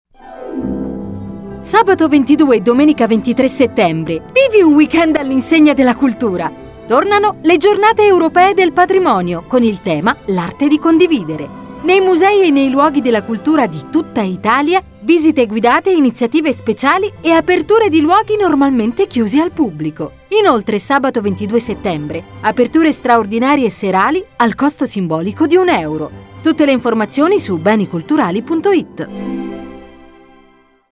Spot radio GEP2018
Spot-radio-GEP2018.mp3